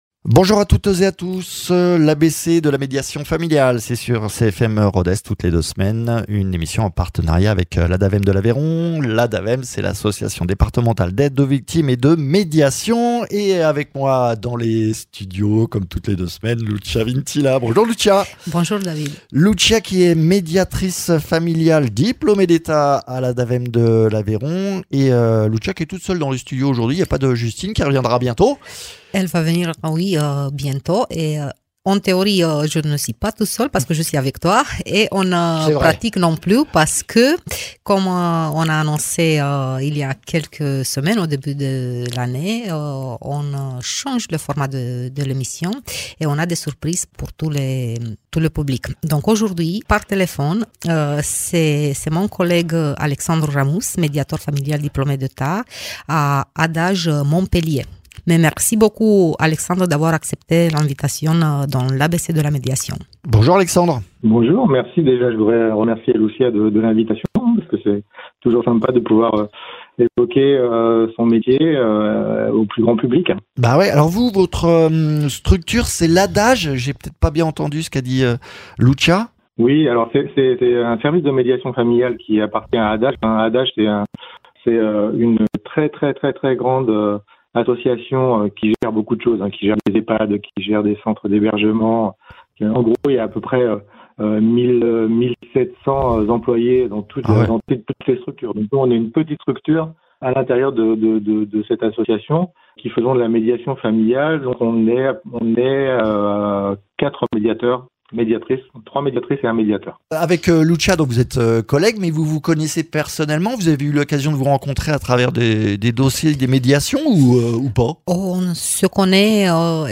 Coup de fil à un médiateur qui exerce du côté de Montpellier pour revenir sur ces expériences à travers plusieurs cas concrets ...